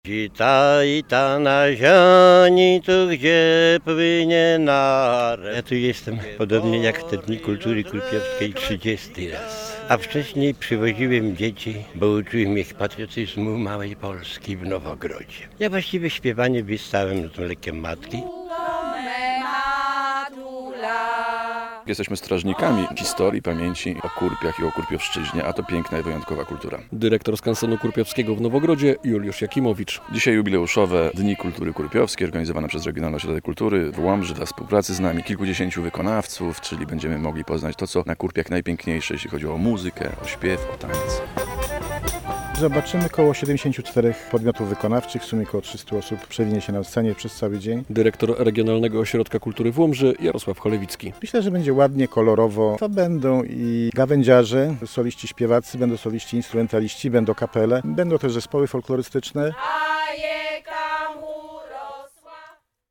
Ponad 70 artystów ludowych wystąpiło podczas Ogólnopolskich Dni Kultury Kurpiowskiej w Nowogrodzie.
Spotkanie w Nowogrodzie to sposób na zachowanie regionalnej tradycji - relacja